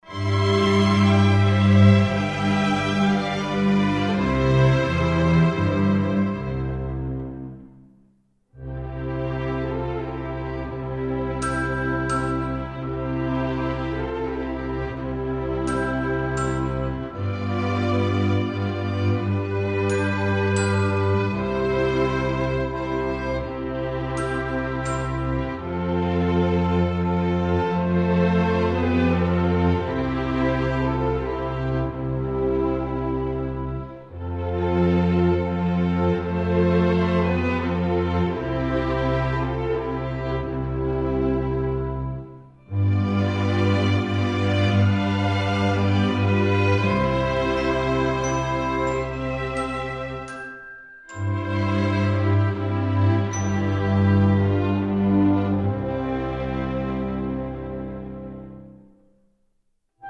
Performers: Royal London Strings